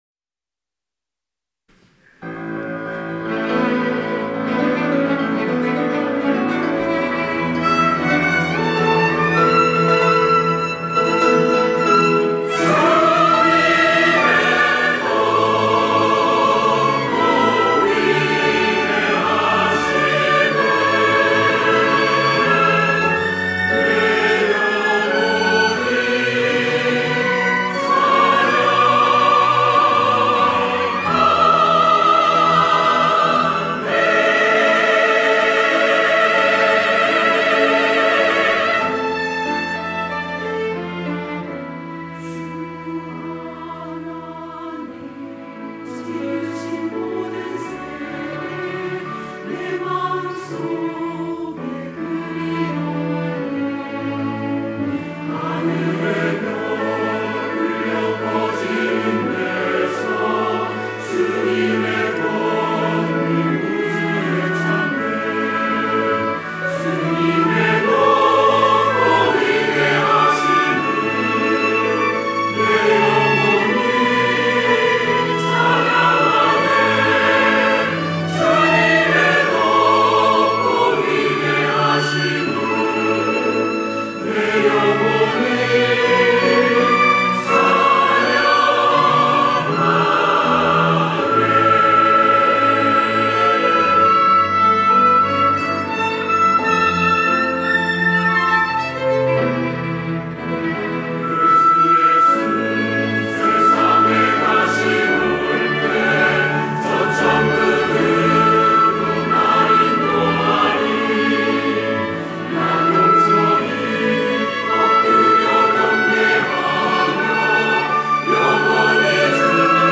할렐루야(주일2부) - 주 하나님 / 살아계신 주
찬양대